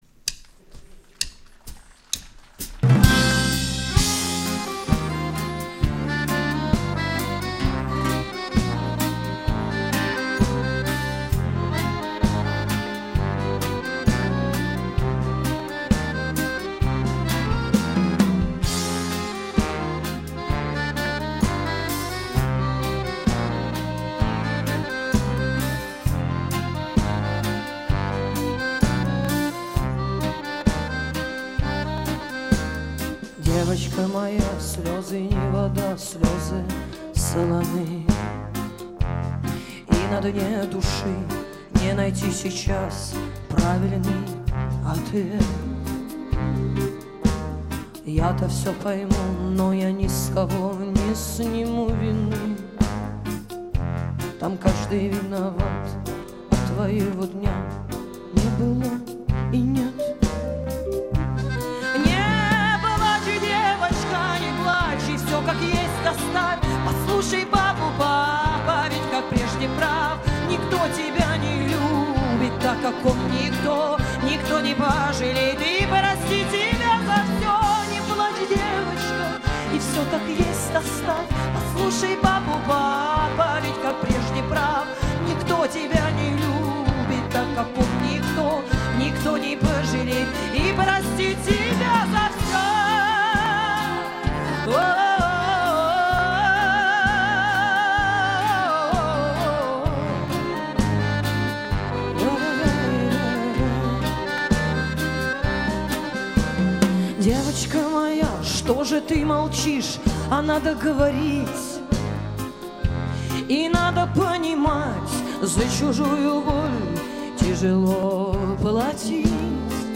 Фолк рок